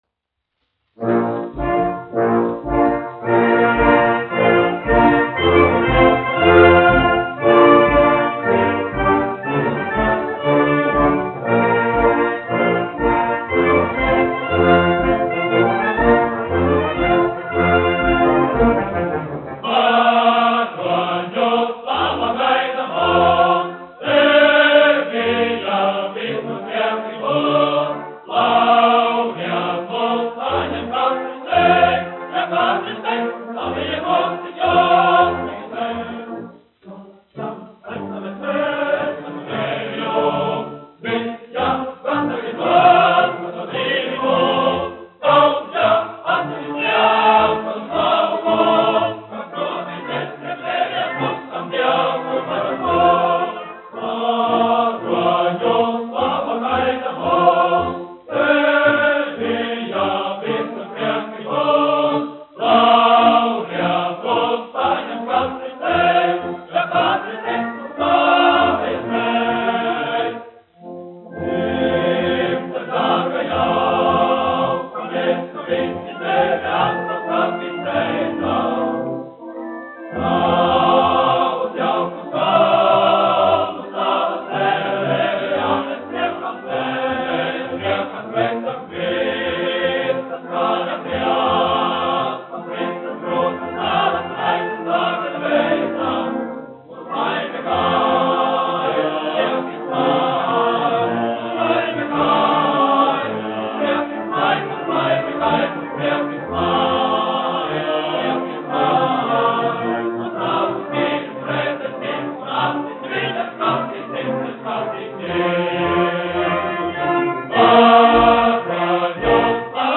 Latvijas 4. Valmieras kājnieku pulka orķestris, izpildītājs
1 skpl. : analogs, 78 apgr/min, mono ; 25 cm
Operas--Fragmenti
Kori (vīru) ar orķestri
Iedziedājis Latvijas kara skolas kadetu koris
Latvijas vēsturiskie šellaka skaņuplašu ieraksti (Kolekcija)